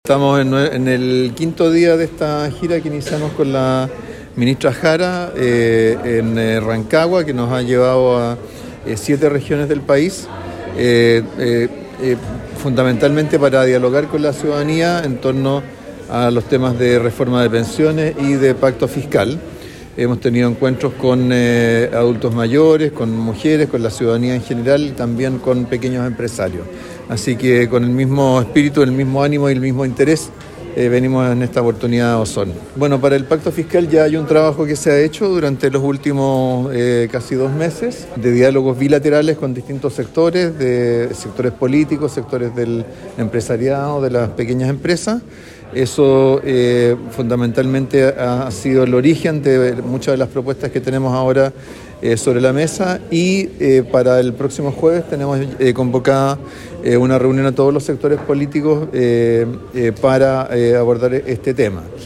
Ministros del Trabajo y Hacienda expusieron en el Teatro Municipal de Osorno
En relación al Nuevo Pacto Fiscal, el Ministro de Hacienda Mario Marcel señaló que se han desarrollado encuentros con la ciudadanía en general, además de informar que existen diálogos bilaterales con distintos gremios para abordar la temática.